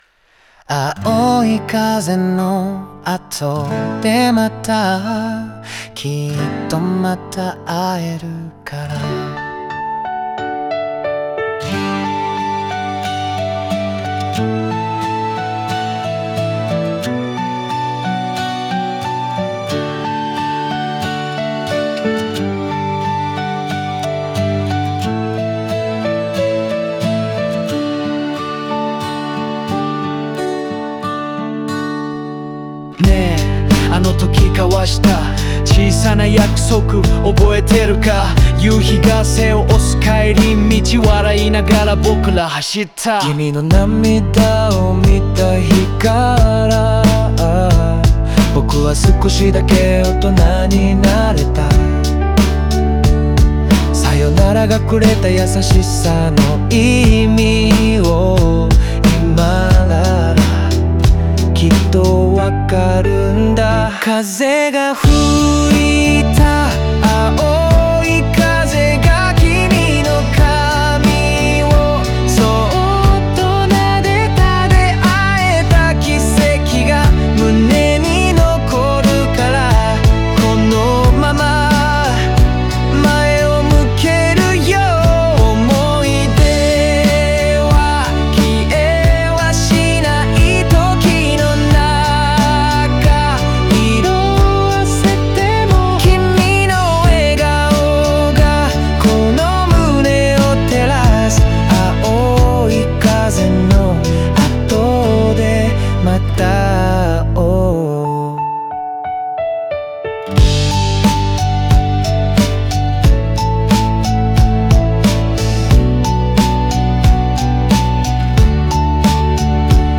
オリジナル曲♪
優しいピアノやRapが、郷愁と青春の風景を想起させ、感情の波を丁寧にすくい上げています。